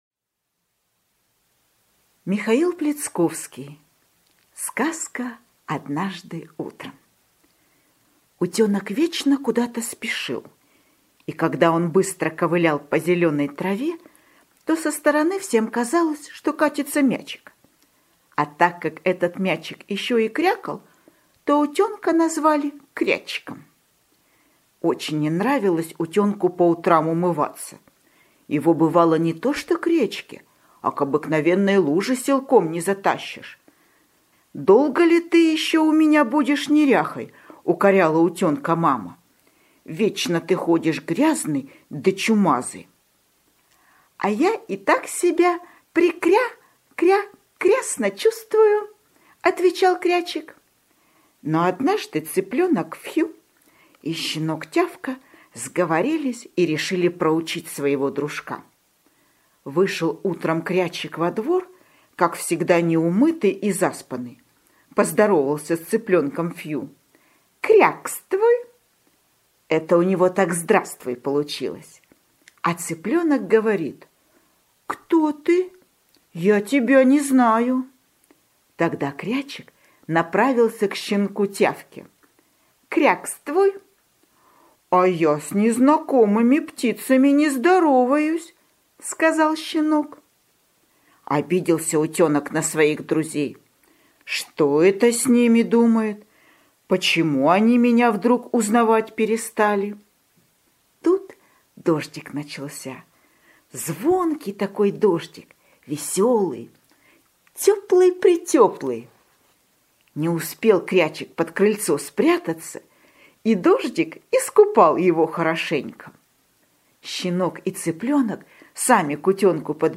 Аудиосказка «Однажды утром»